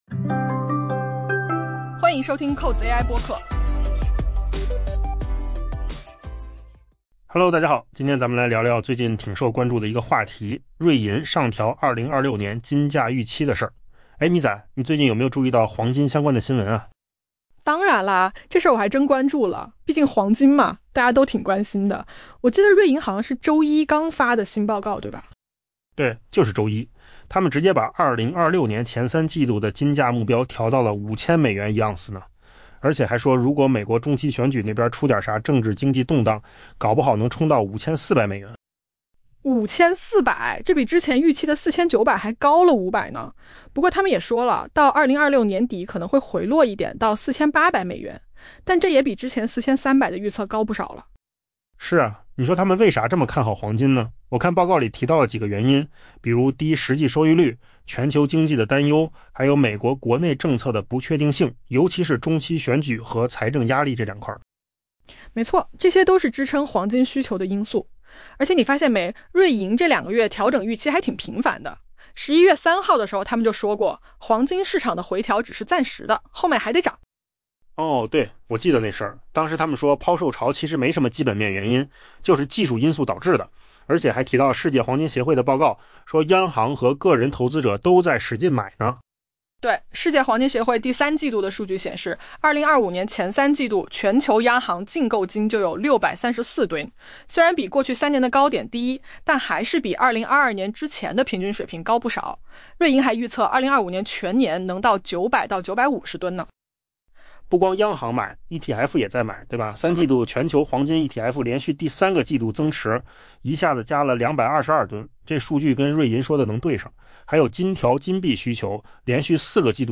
AI 播客：换个方式听新闻 下载 mp3 音频由扣子空间生成 在周一发布的新报告中，瑞银 （UBS） 宣布， 将 2026 年前三季度的黄金价格目标上调至每盎司 5000 美元。